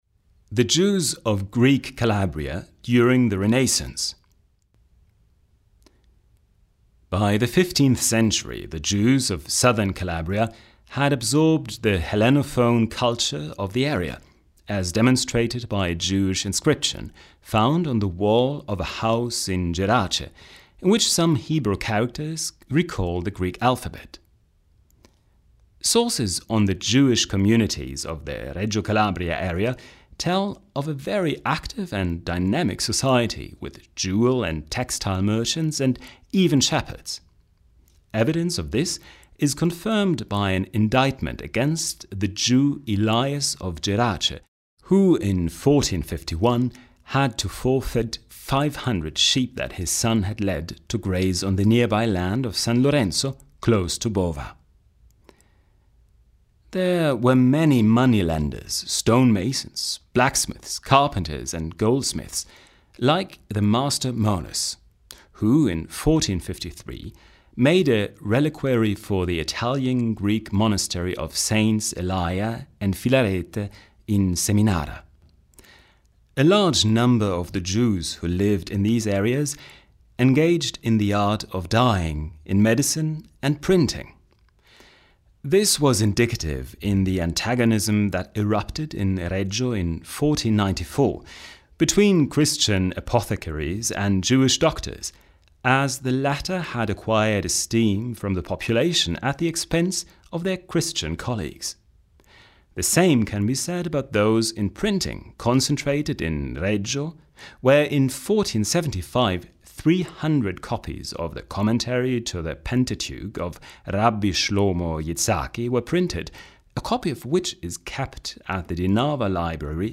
HOME AUDIOGUIDA DELLA GIUDECCA